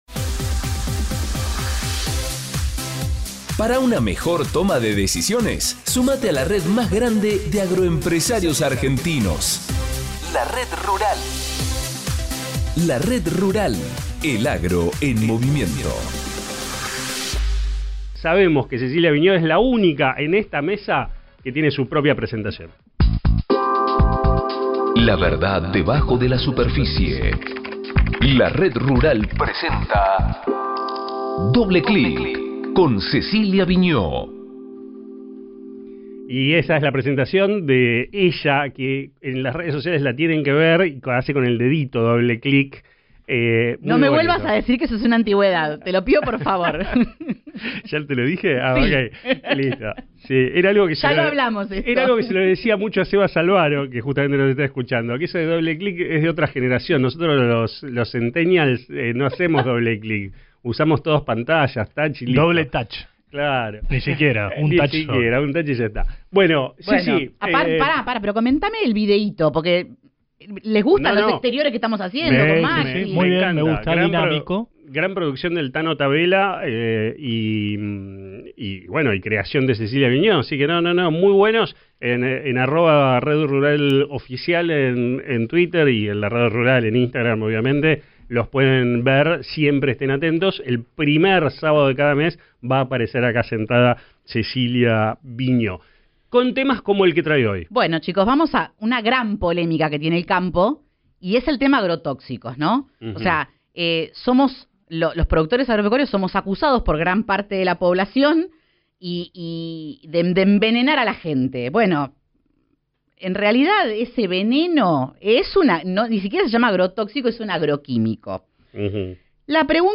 En el programa de radio La Red Rural